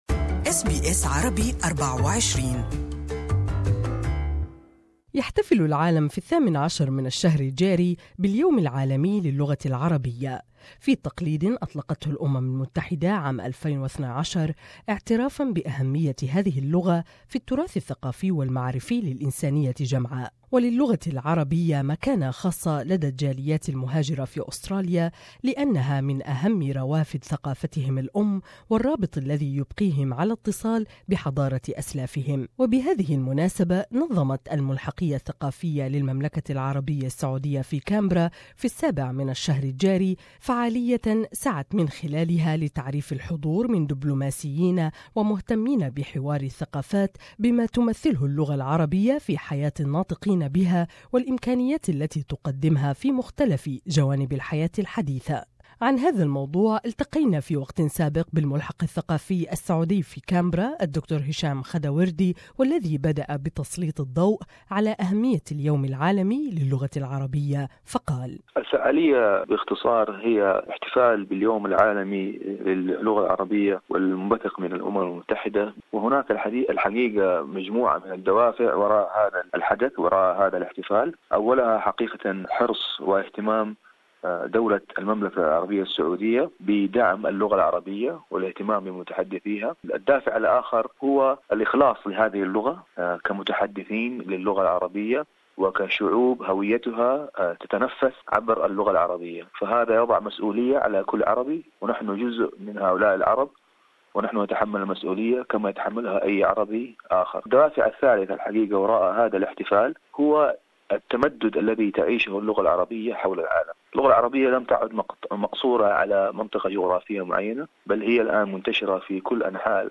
For more, listen to the above interview with the Saudi Cultural Attache in Canberra Dr. Hisham Khaddawardi, who began by highlighting the importance of the World Day of Arabic Language